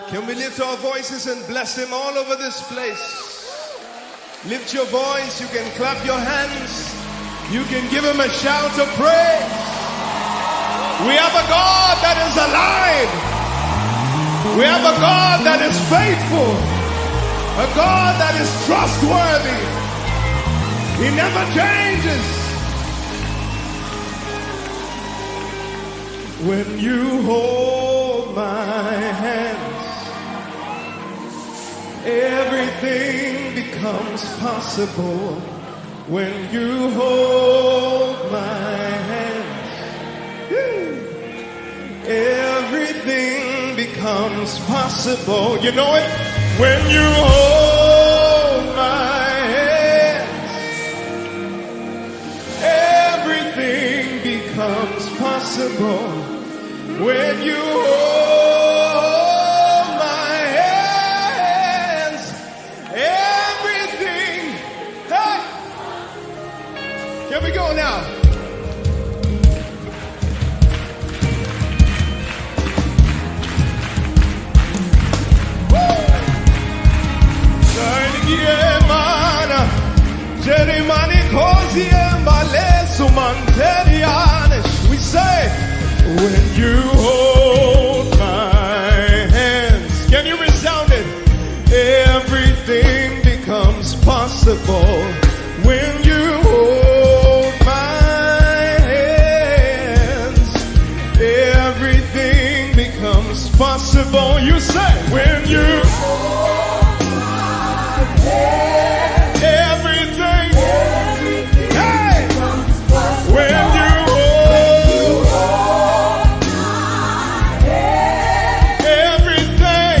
electronic
Gospel Music